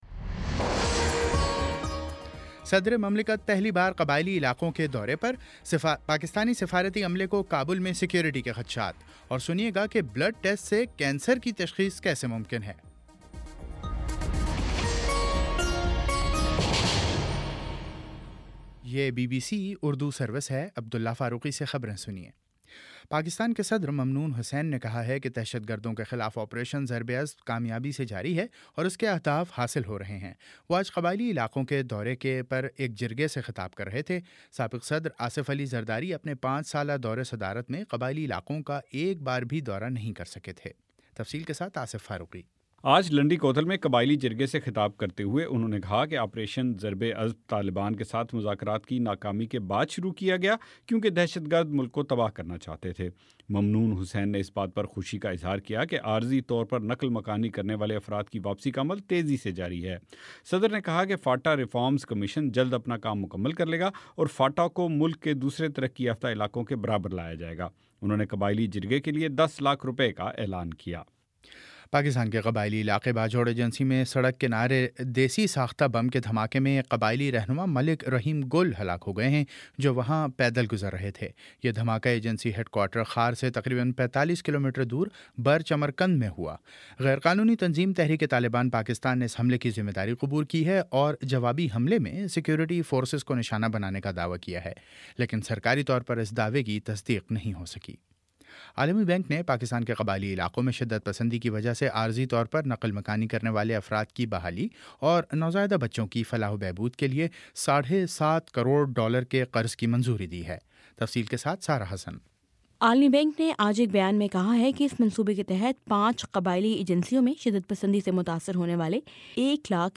اگست 27: شام پانچ بجے کا نیوز بُلیٹن